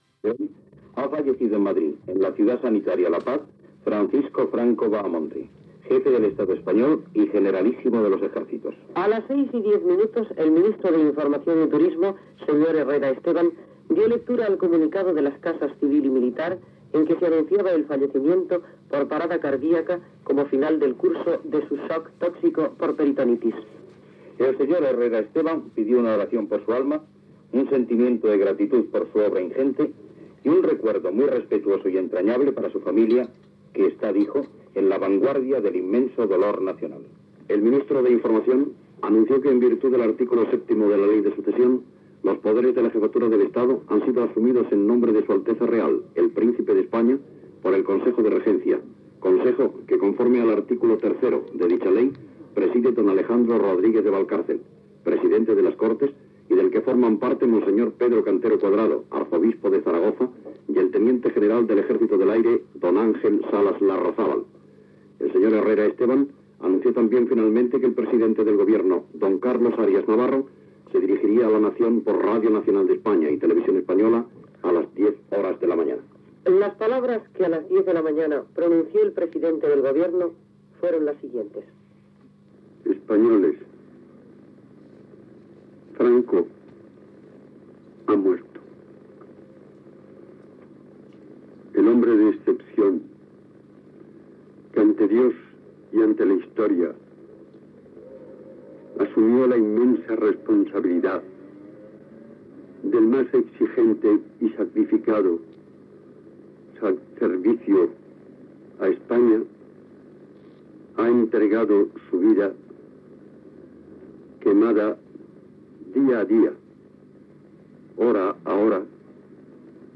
Informatiu de les 14:30 hores. Cronologia dels fets succeïts després de l'anunci de la mort del cap d'Estat, el "generalísimo" Francisco Franco. Paraules del president del govern espanyol Arias Navarro
Informatiu